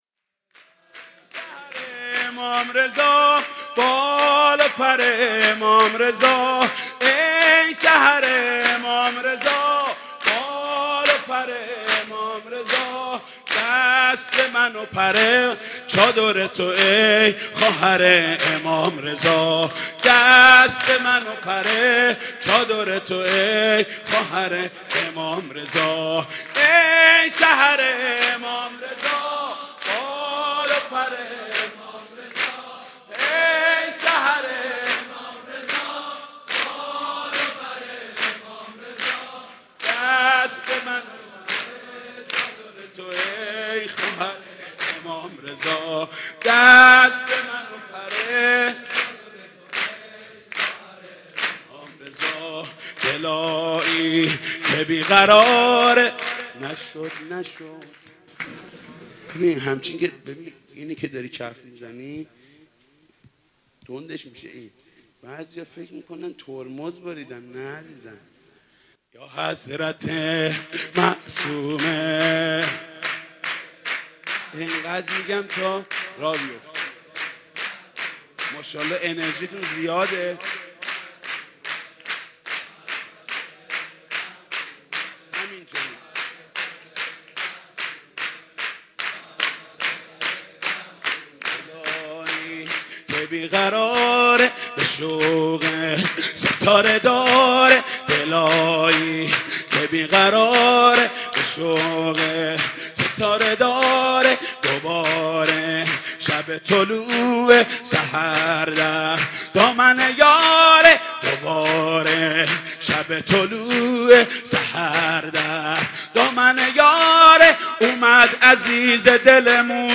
سرود: ای سحر امام رضا، بال پر امام رضا